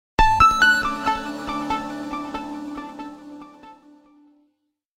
Tono Simple sms alert